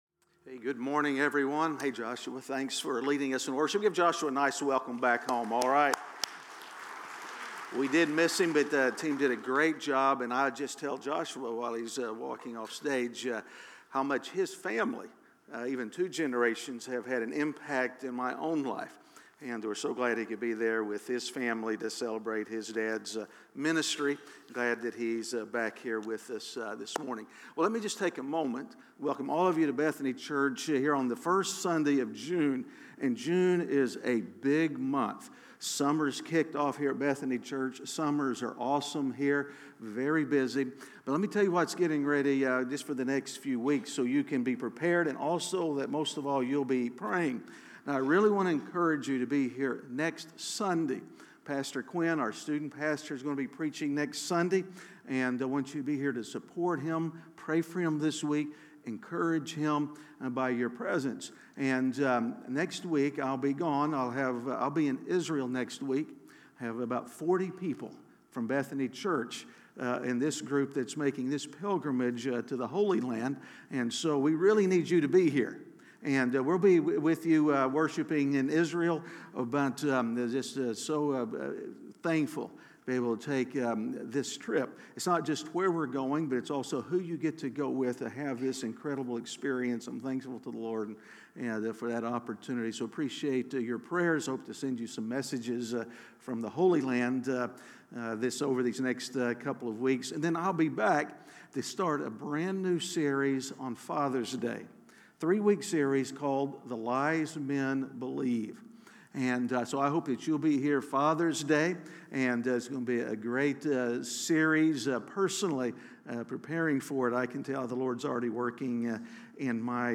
Missed a Sunday? You can go back and catch up on any of the sermons you missed.